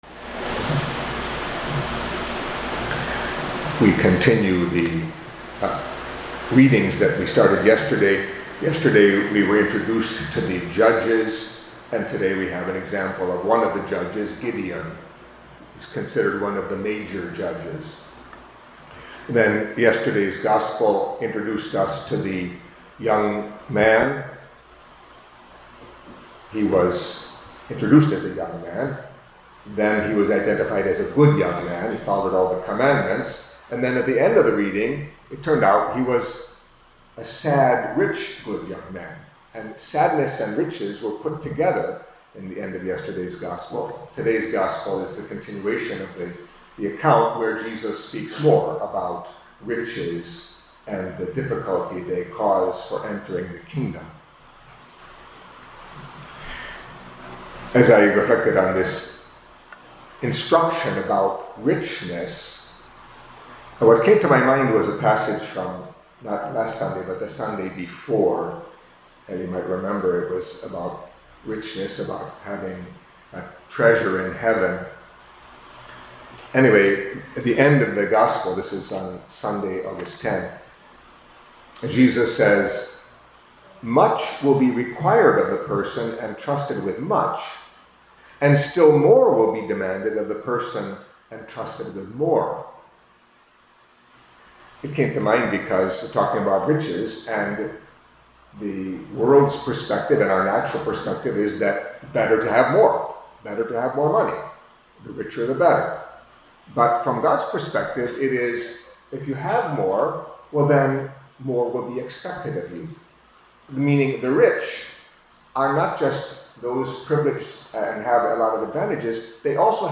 Catholic Mass homily for Tuesday of the Twentieth Week in Ordinary Time